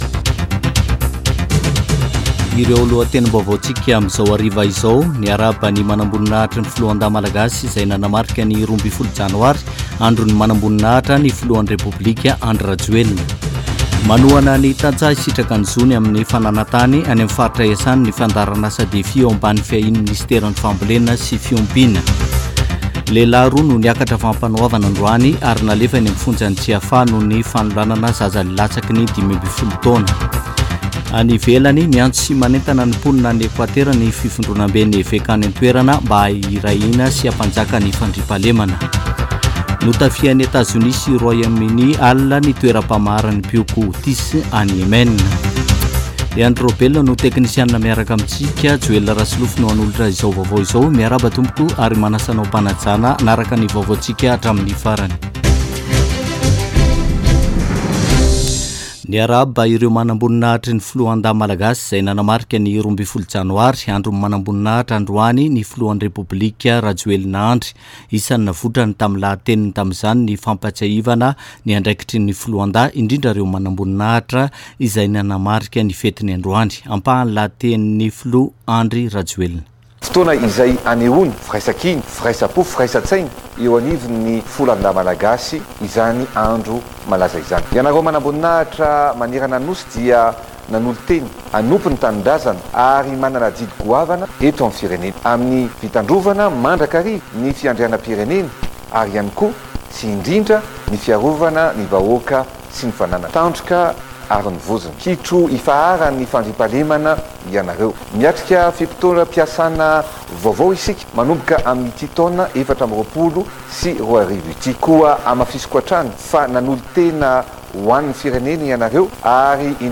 [Vaovao hariva] Zoma 12 janoary 2024